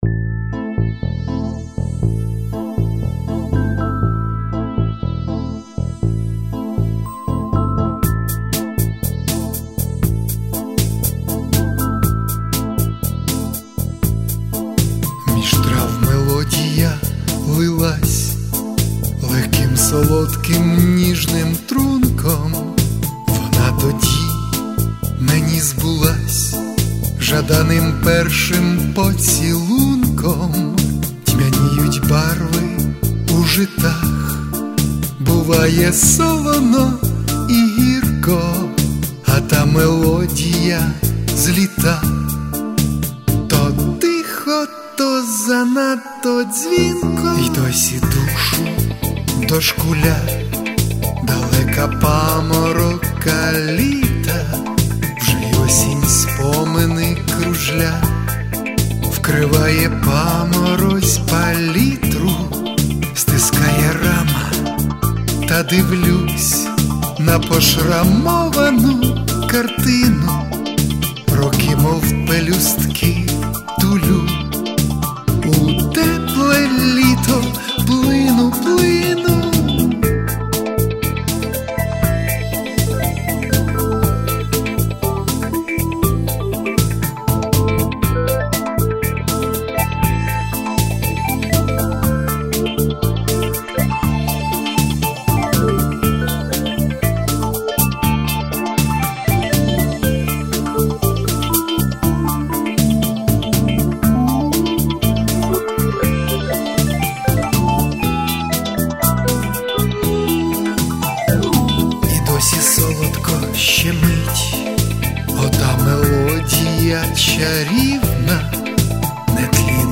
Рубрика: Поезія, Авторська пісня
12 12 12 Гарна робота! 16 16 16 Добре передав лірично-задумливий настрій віршу! give_rose give_rose give_rose friends friends friends (А от те, що ти гарний клавішник - відчувається.
Відповім так: хотілося максимально наблизитись до живого виконання, ніби грає жива команда в реальному часі.
Все в такому задумливому ля-мінорі friends